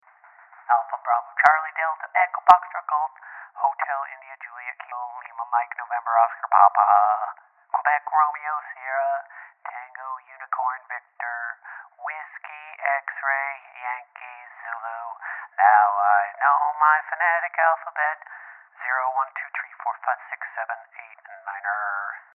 I like using the phonetic alphabet and can sing the ABC song using it.
alpha-bravo-charlie-song.mp3